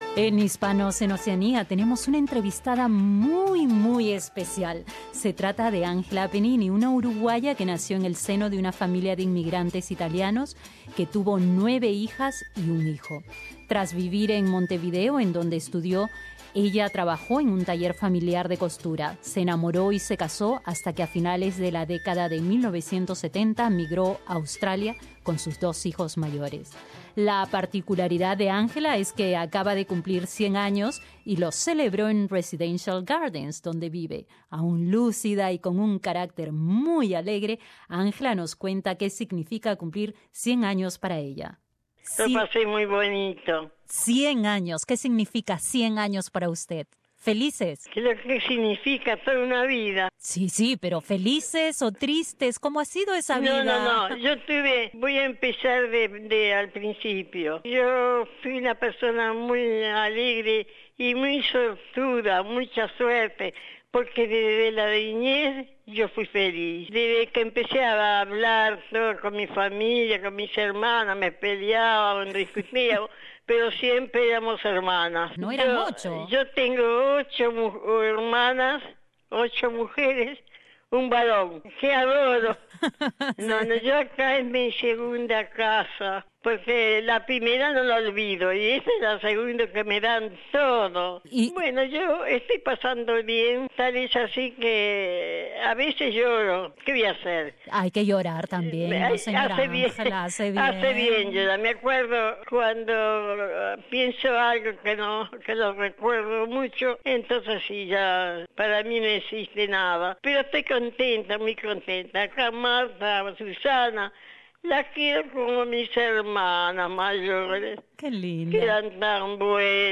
En Hispanos en Oceanía tenemos una entrevistada muy especial